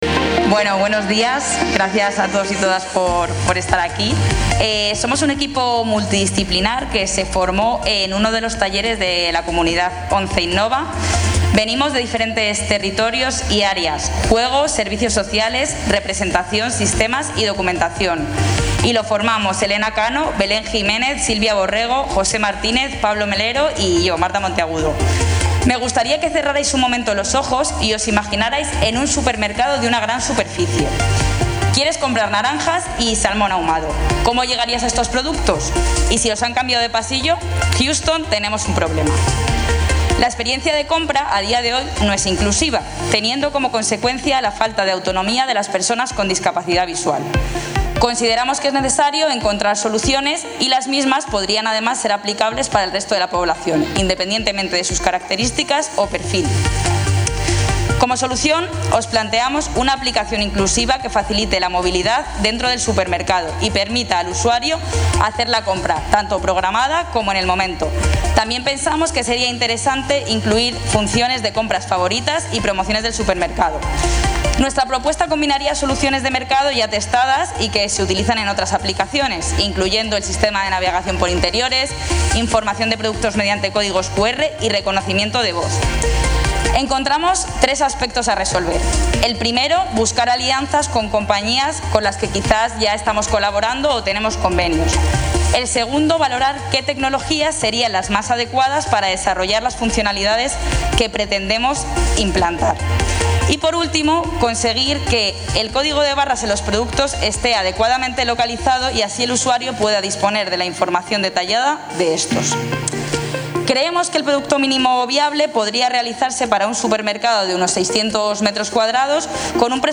en la gala de premios.